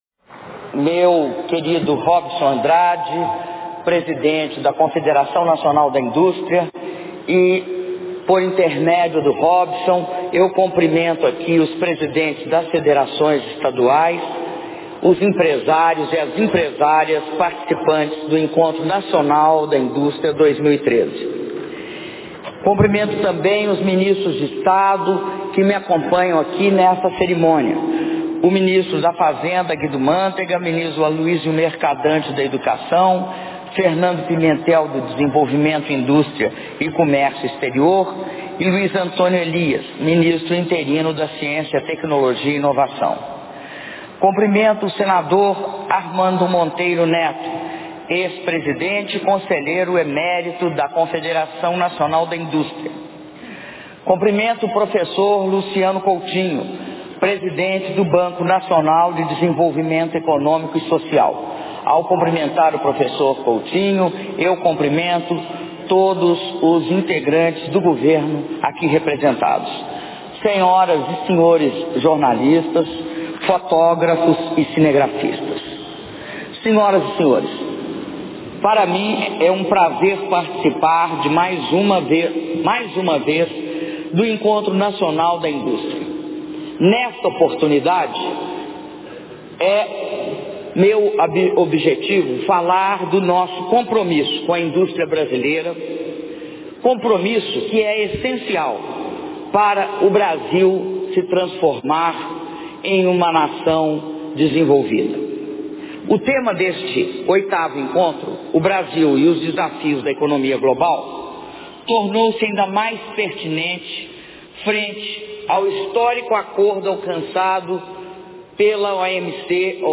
Áudio do discurso da Presidenta da República, Dilma Rousseff, na solenidade de abertura do 8º Encontro Nacional da Indústria - ENAI 2013 - Brasília/DF